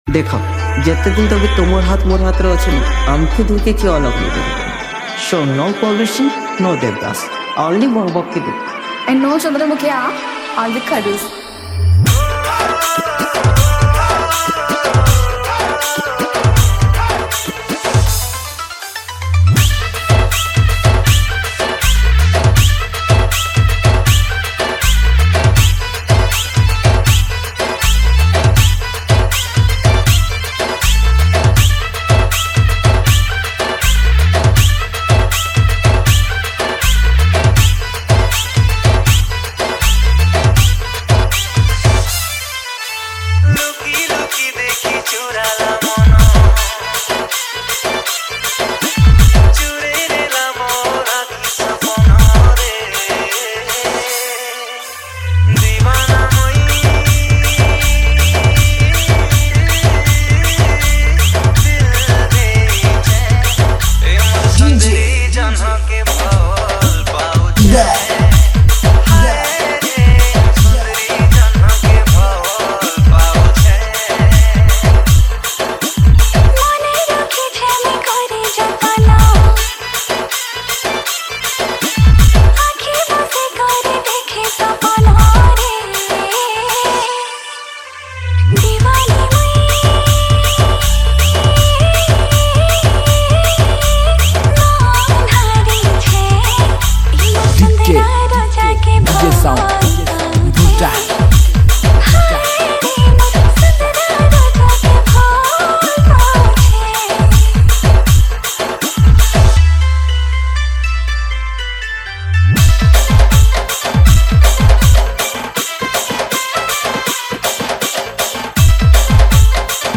SAMBALPURI LOVE DJ REMIX